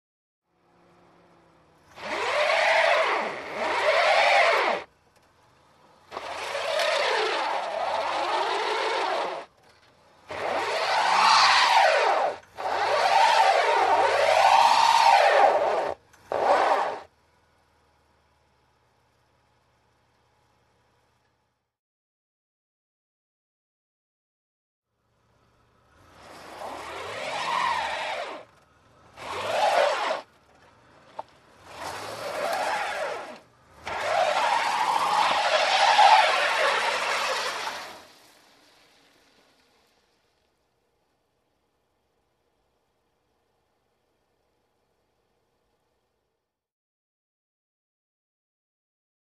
Звуки машины, снега
Шум шин по снегу, машина застряла и скользит